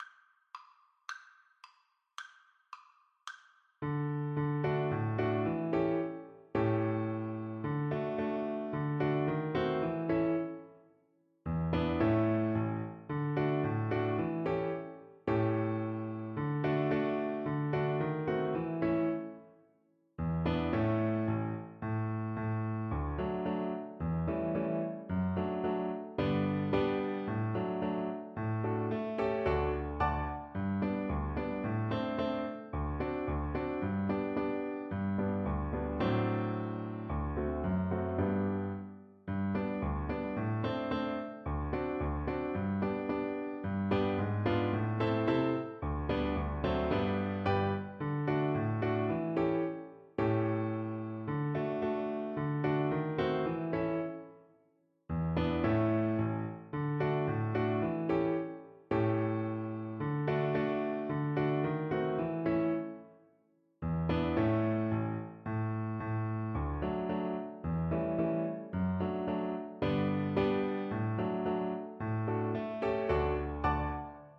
Classical Tárrega, Francisco Rosita Polka Violin version
Violin
D major (Sounding Pitch) (View more D major Music for Violin )
2/4 (View more 2/4 Music)
= 110 Fast and bright
Classical (View more Classical Violin Music)
Polkas for Violin